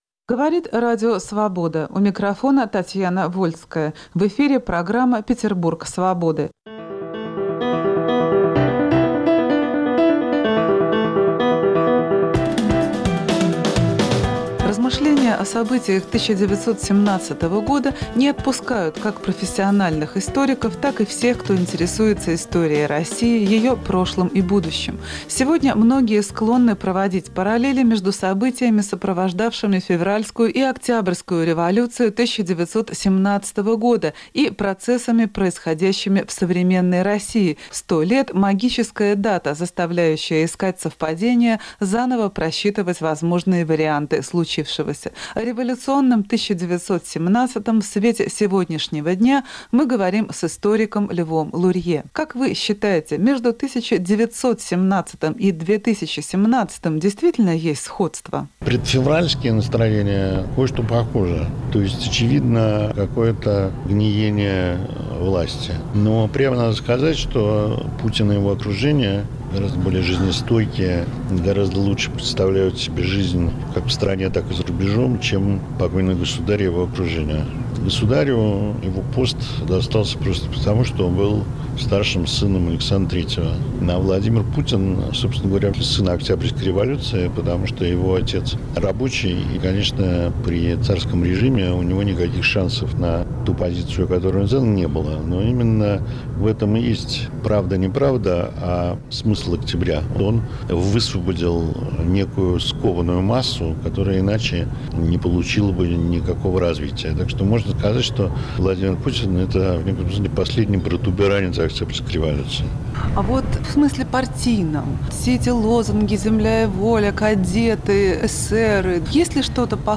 О революционном 1917 в свете сегодняшнего дня мы говорим с историком Львом Лурье.